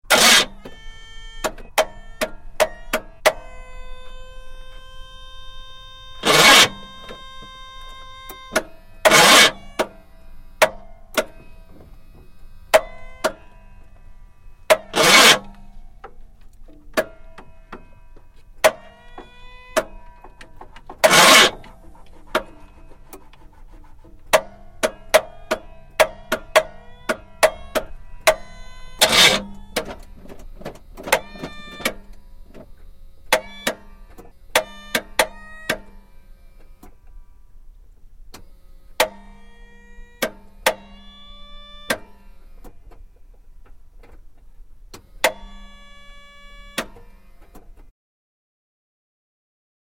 Звуки разбивания машины
Шум неисправного двигателя автомобиля